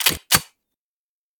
select-pistol-2.ogg